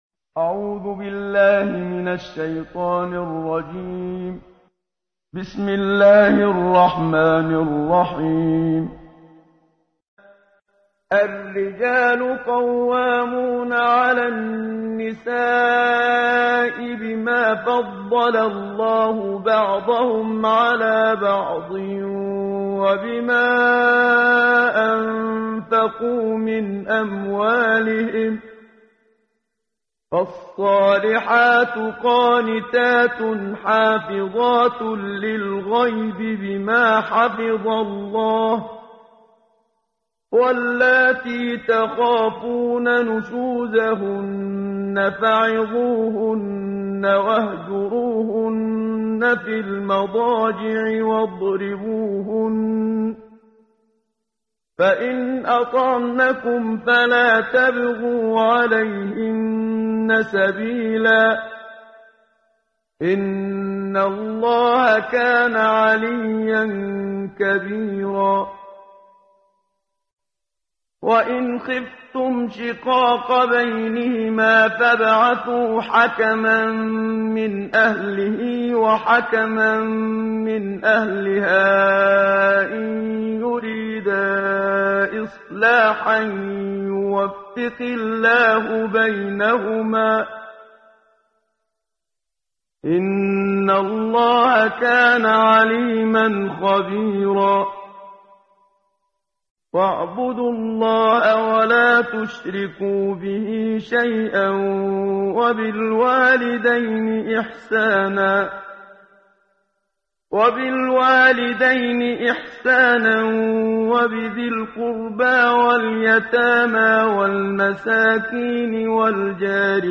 قرائت قرآن کریم ، صفحه 84، سوره مبارکه نساء آیه 34 تا 37 با صدای استاد صدیق منشاوی.